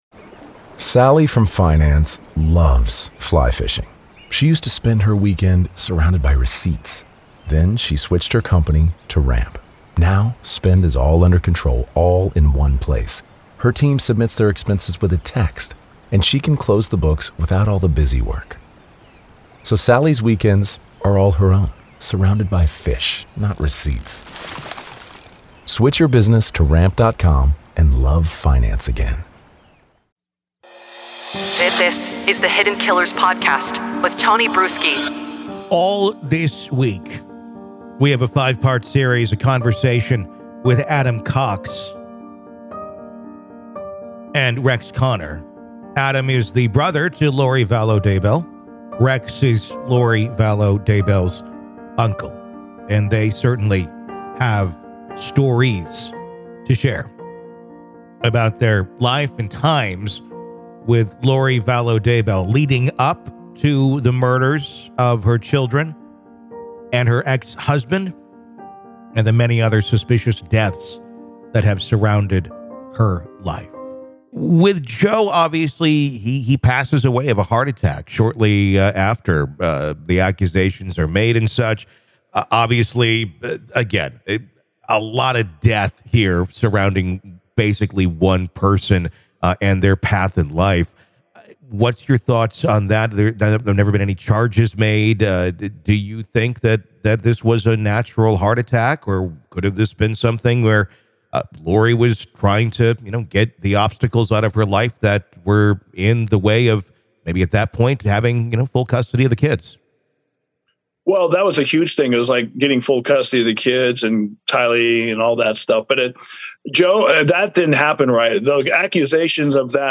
In a compelling discussion